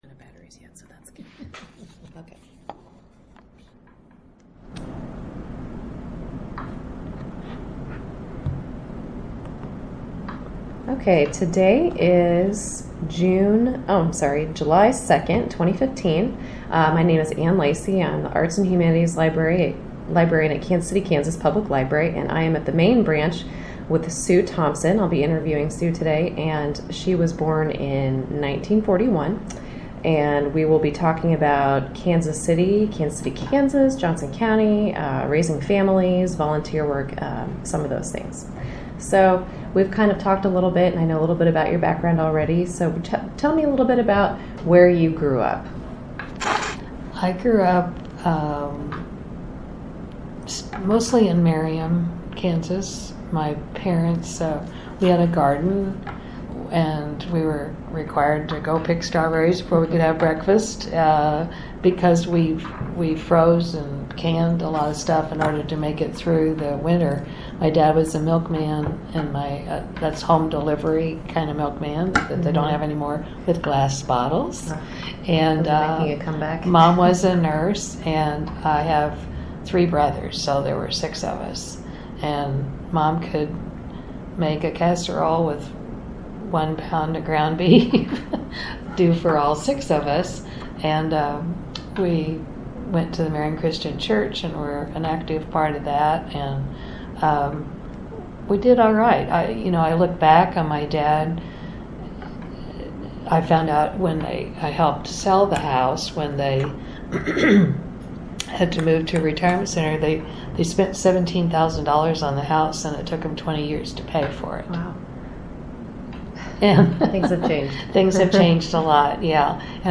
07/08/2015 · Genealogy Gallery · Midwest Genealogy Center